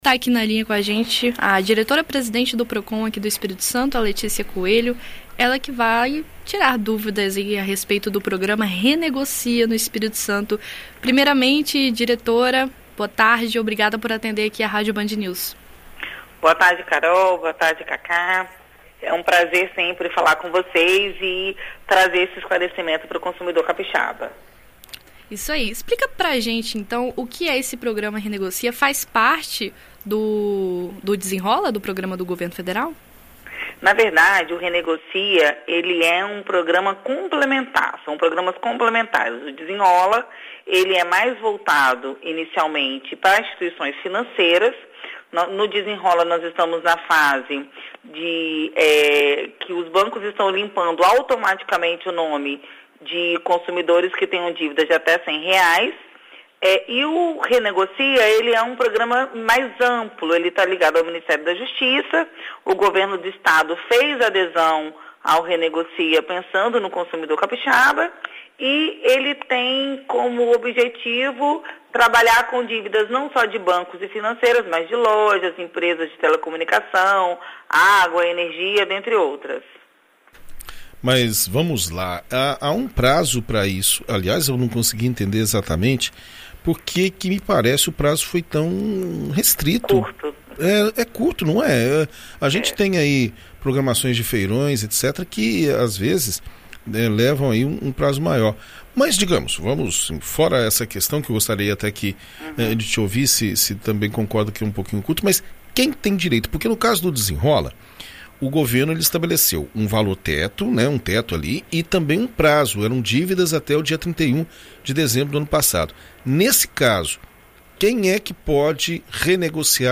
Em entrevista à BandNews FM ES, nesta quarta-feira (26), a Diretora-Presidente do Procon/ES, Letícia Coelho, explica quais os critérios do programa Renegocia.